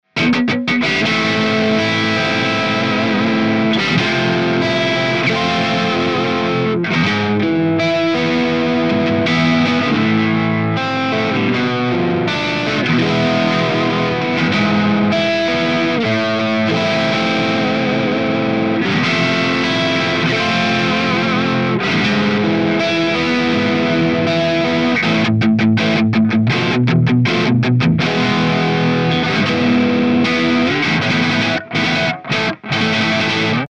It's fully loaded for any Hi-Gain application. 100 Watts of sofisticated Rock and Metal tones, It's all about gain!
Chords
RAW AUDIO CLIPS ONLY, NO POST-PROCESSING EFFECTS